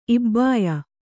♪ ibbāya